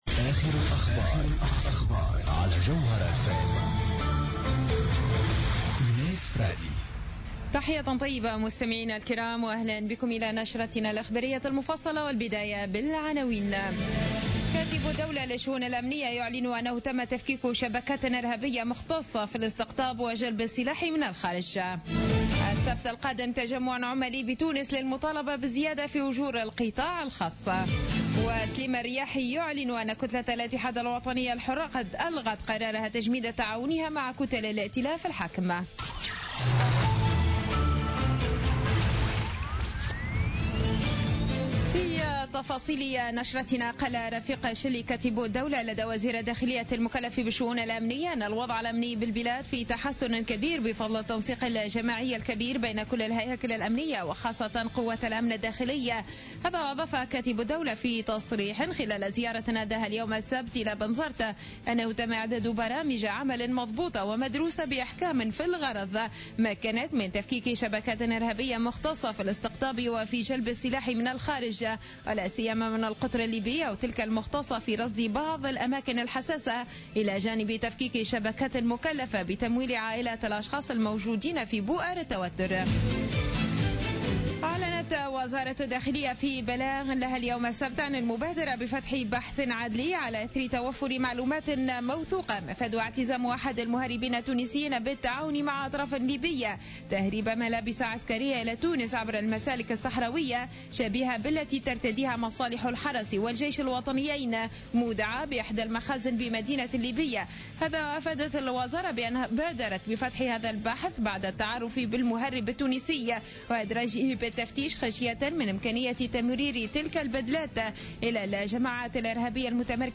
نشرة أخبار السابعة مساء ليوم السبت 24 أكتوبر 2015